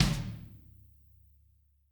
tom5.ogg